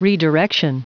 Prononciation du mot redirection en anglais (fichier audio)
Prononciation du mot : redirection